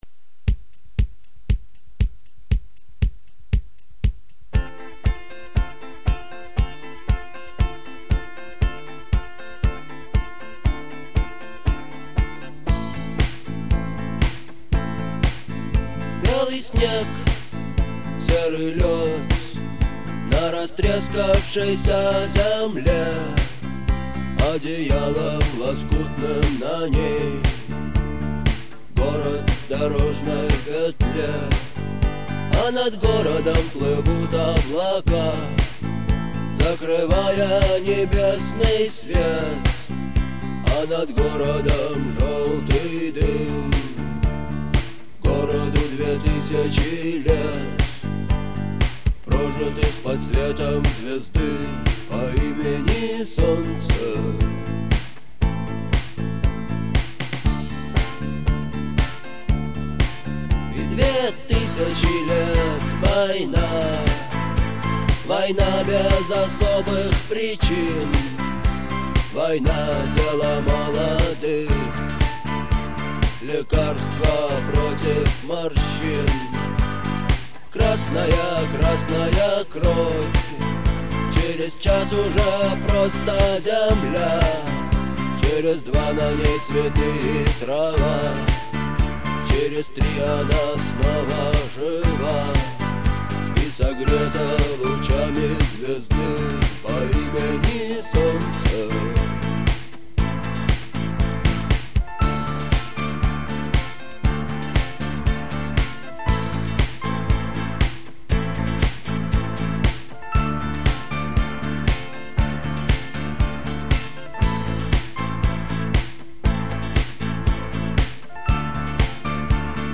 Слушать на гитаре